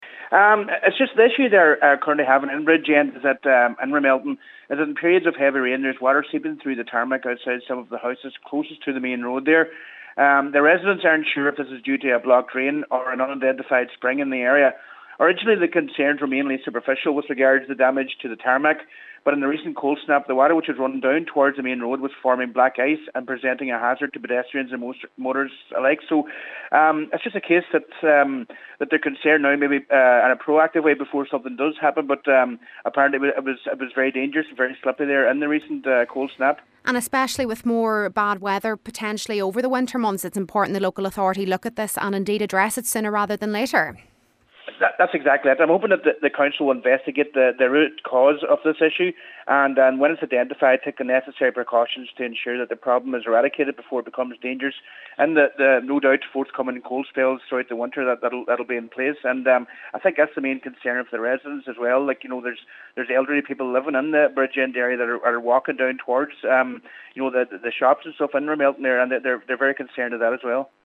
Cllr. Adrian Glackin says not only that but in periods of cold weather the overflow is turning to black ice which is providing a potential hazard for pedestrians and motorists.
He says the Council must take urgent action: